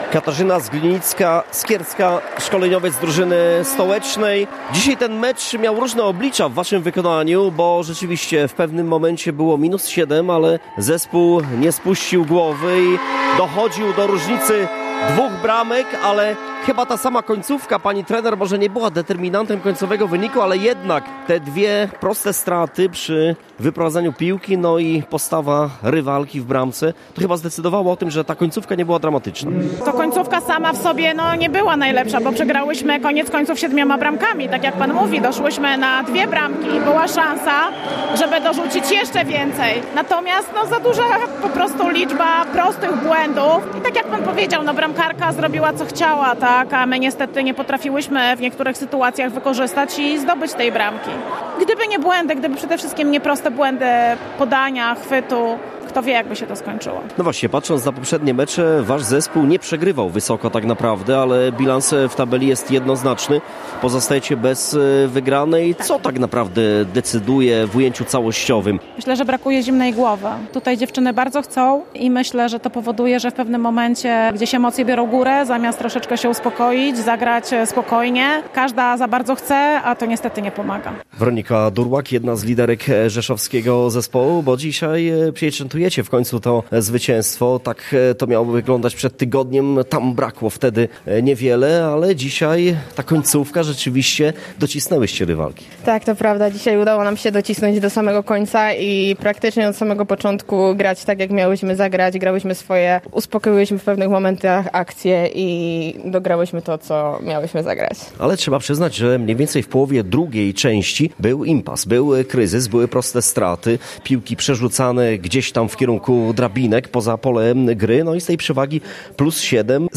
Komentarze po meczu